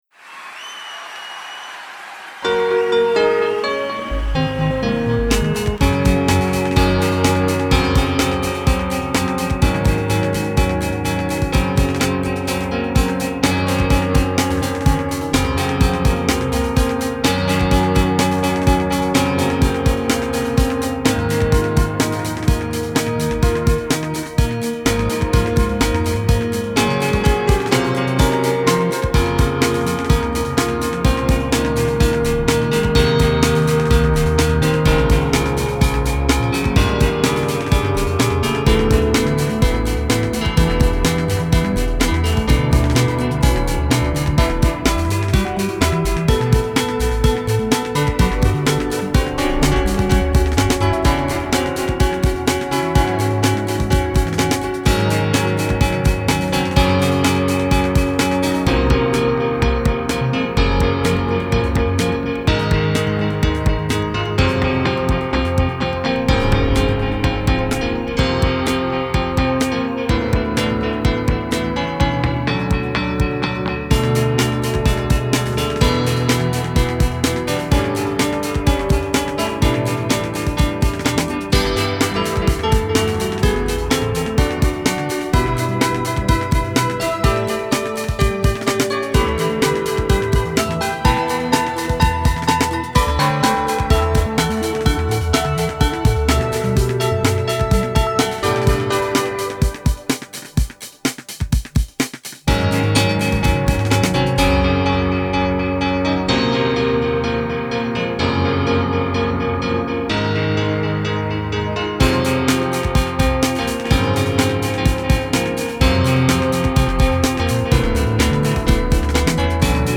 ポップロング明るい